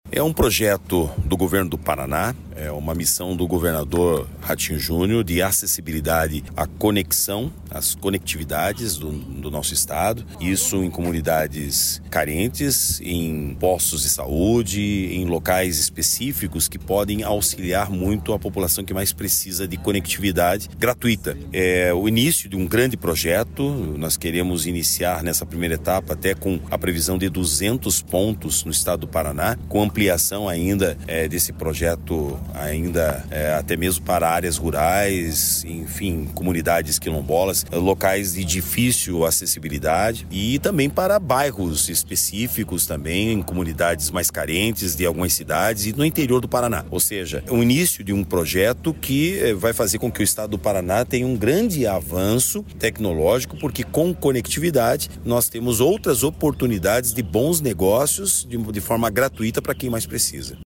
Sonora do secretário da Inovação, Modernização e Transformação Digital, Marcelo Rangel, sobre a instalação de wi-fi gratuito em UPA de Pato Branco